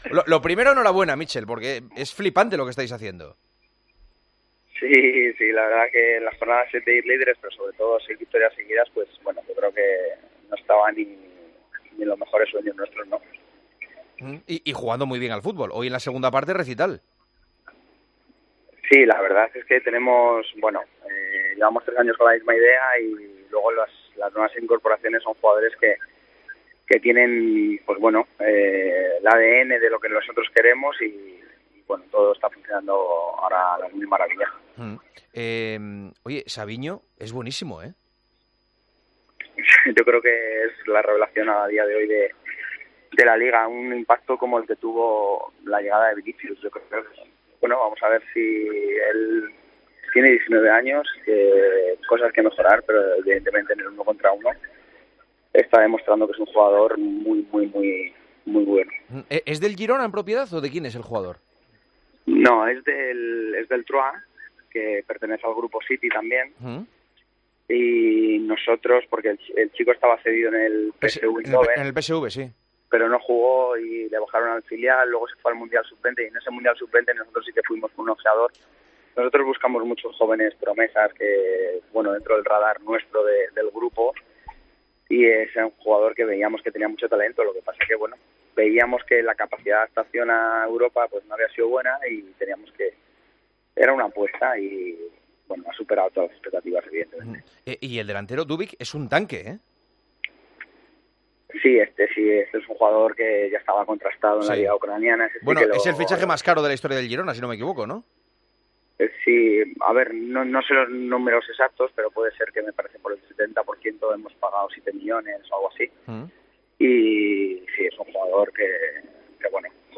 La llamada a Míchel, entrenador del Girona, era casi obligatoria para Juanma Castaño en El Partidazo de COPE de este miércoles, en el que el equipo catalán es líder en solitario de Primera División.